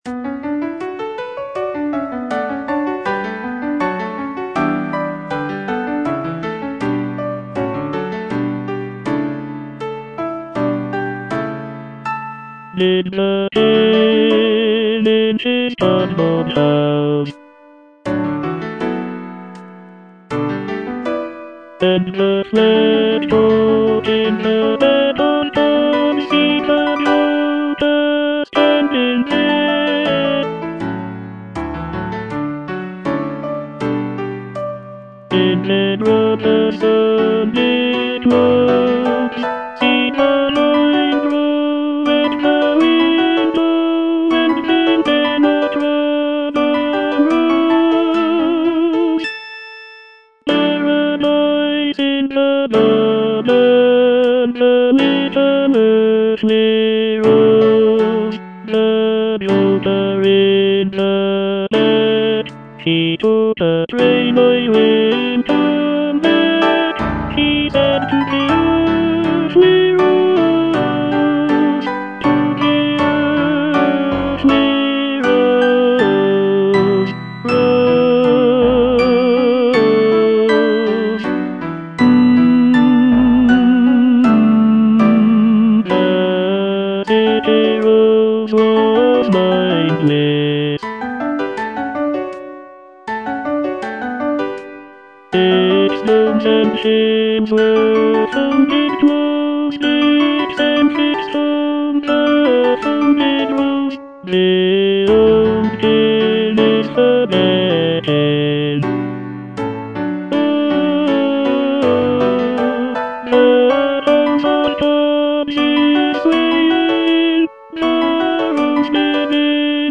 Tenor I (Voice with metronome)
choral work
With its lush textures and haunting melodies